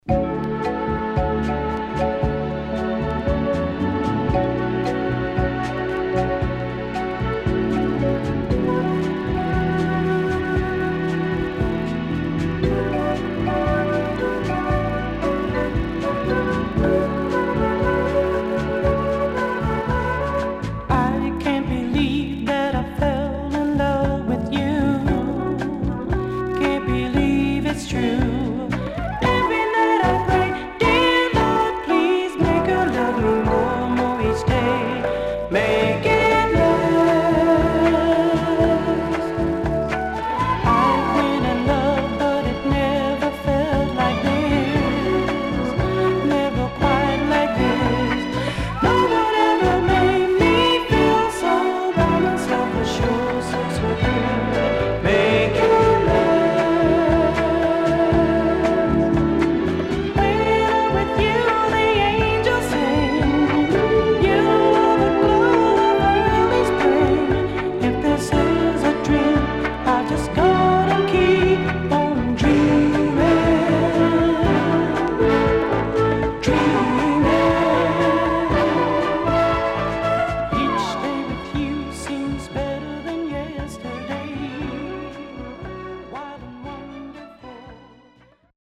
HOME > Back Order [SOUL / OTHERS]
SIDE A:盤質は良好です。